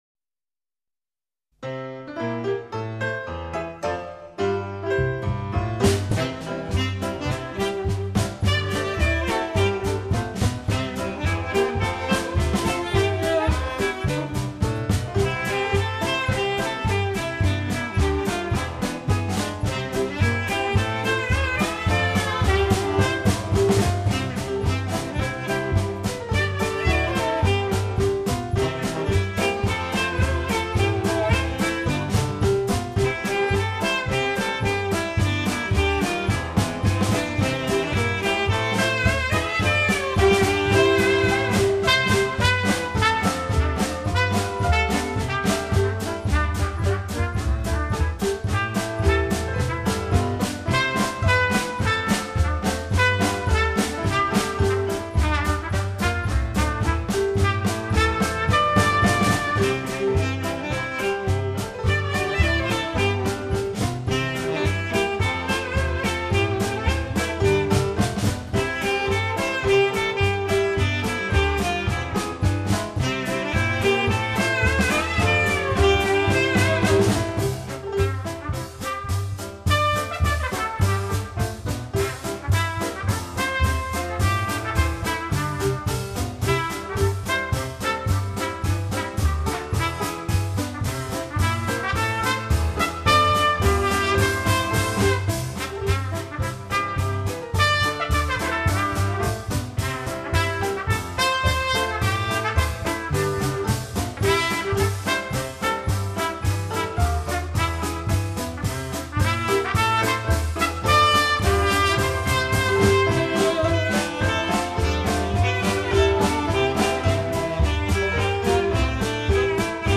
現在聽到的這支樂曲 , 就是這些老樂師演奏的原汁原味 .